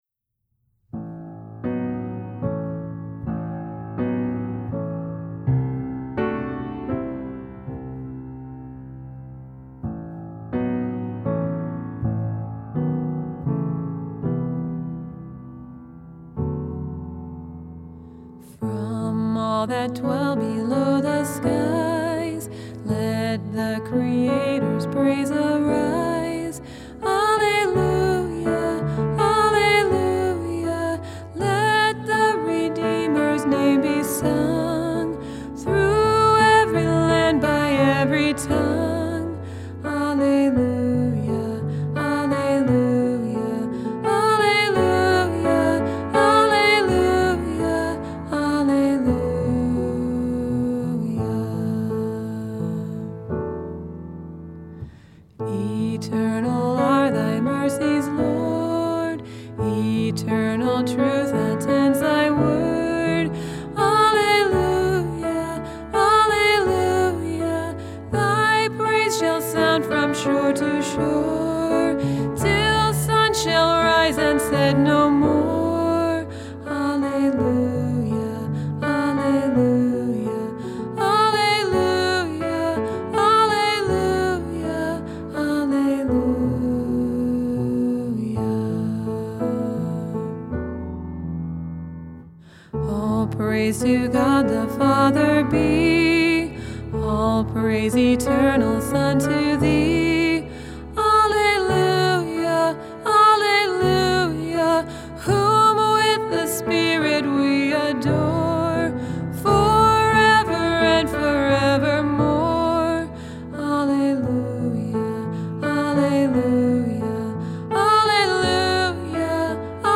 Hymn
The Hymnal Project